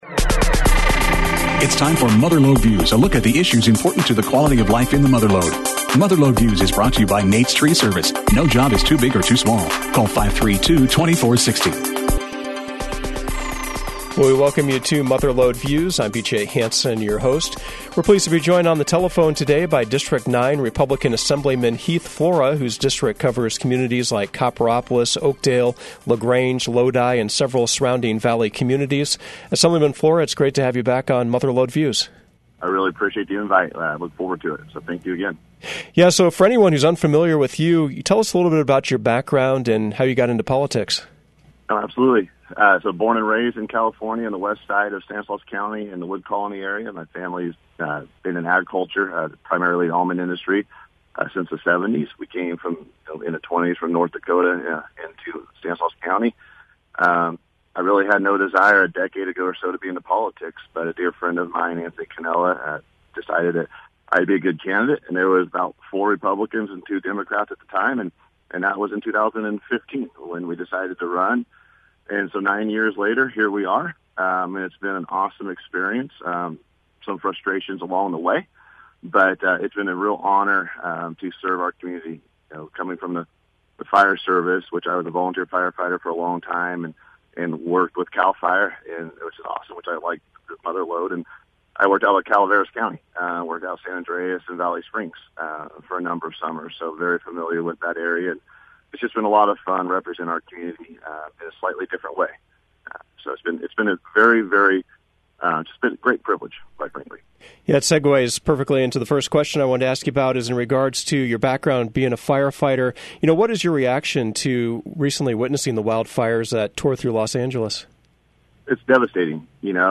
Mother Lode Views featured District 9 California Republican Assemblyman, Heath Flora.